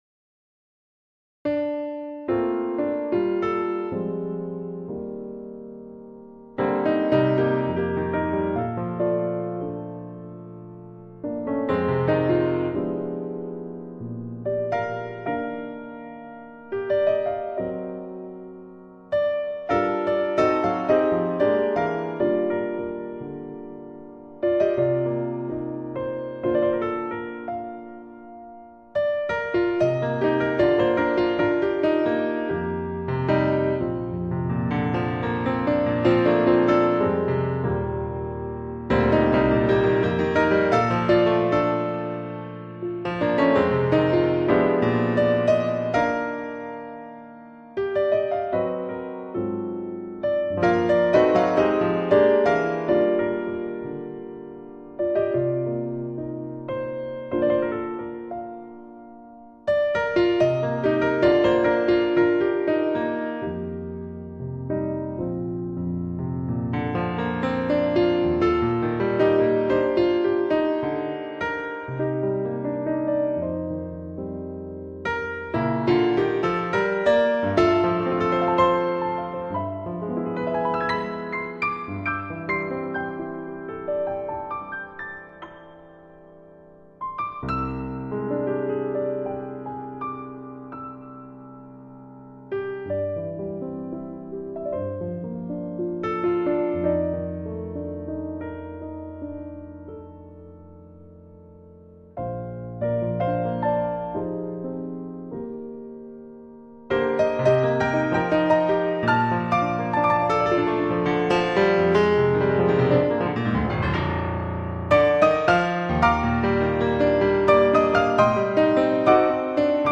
Love Songs
Stage Piano